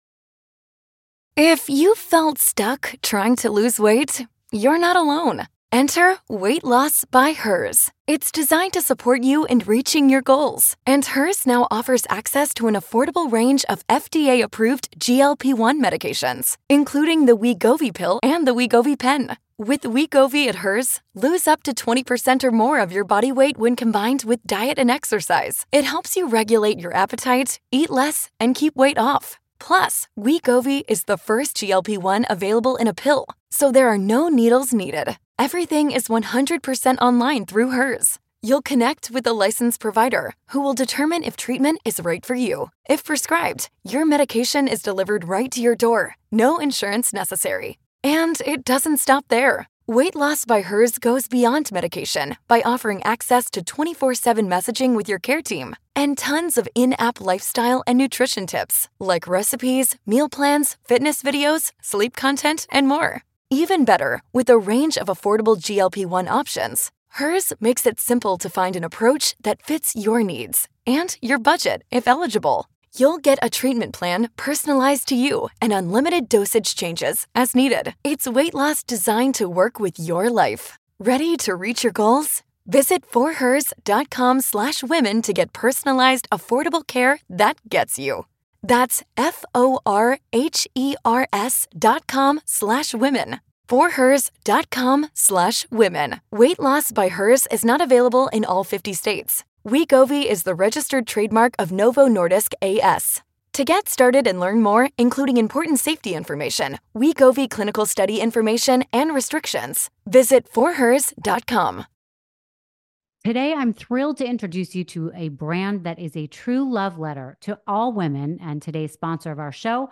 Today's guest is the super talented Carnie Wilson!
Don't miss Rachel and Carnie's conversation filled with lots of laughs and tons of great stories!